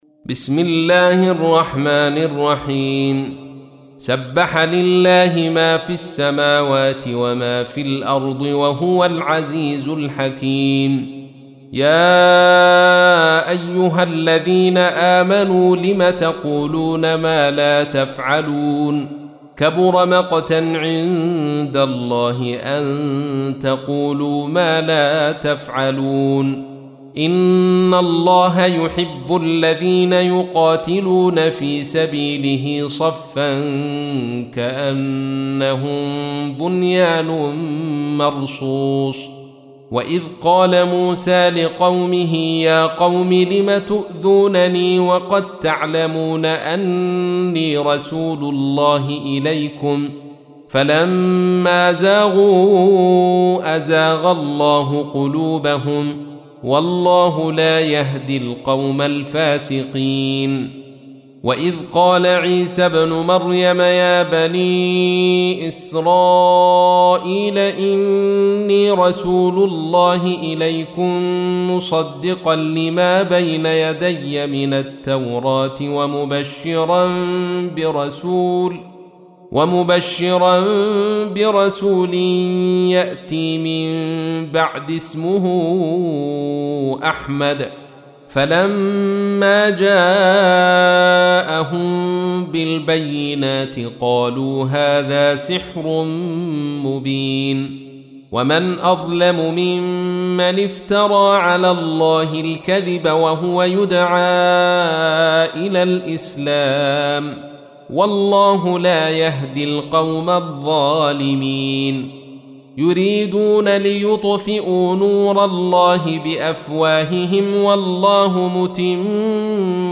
تحميل : 61. سورة الصف / القارئ عبد الرشيد صوفي / القرآن الكريم / موقع يا حسين